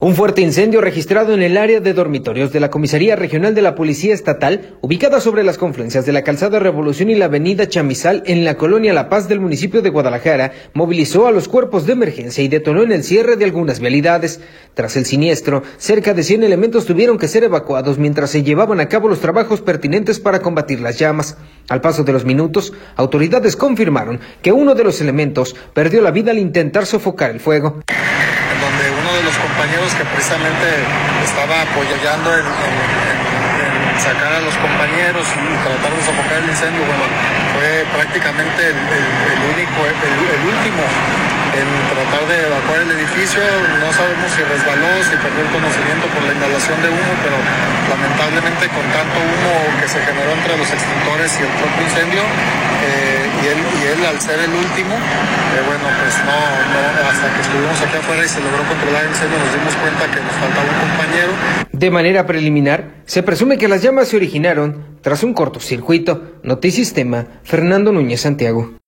Al paso de los minutos autoridades confirmaron que uno de los elementos perdió la vida al intentar sofocar las llamas, informa Juan Pablo Hernández, secretario de Seguridad de Jalisco.